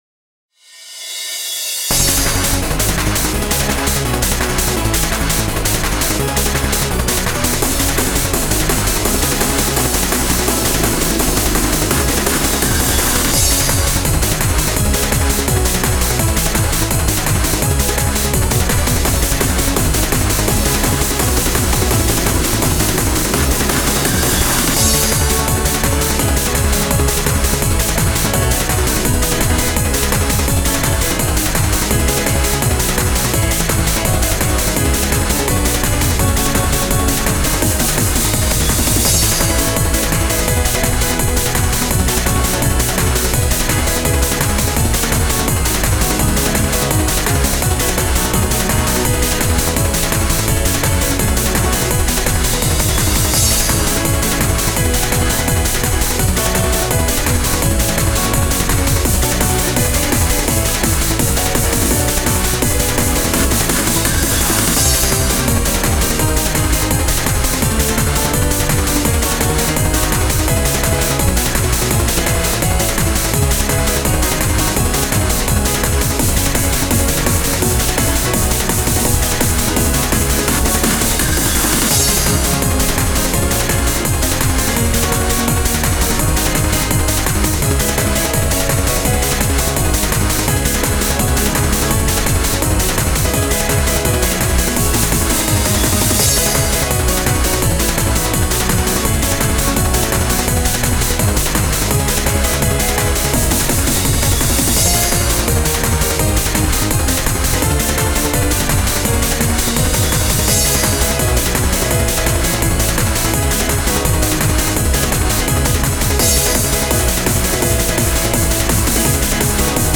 Trance Core
曲調としては双方がメロディ重視なのでぶつかり合う感じです。